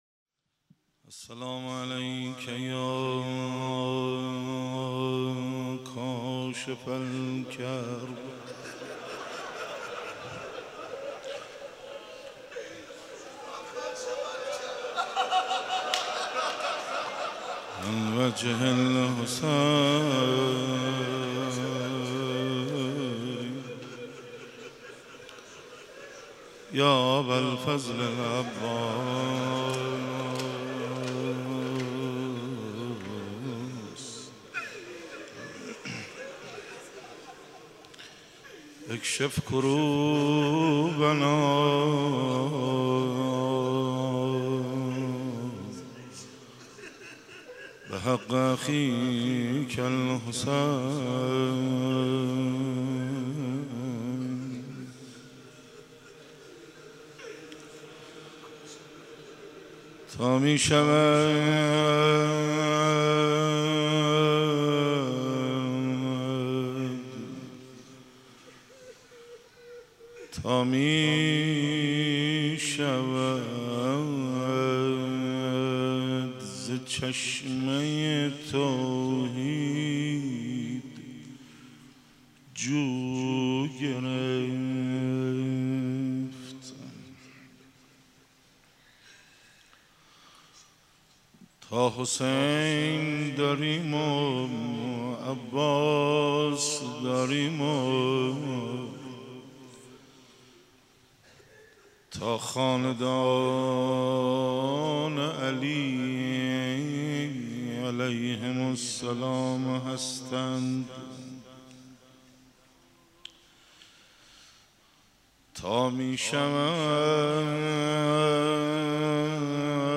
روضه
مداحی.mp3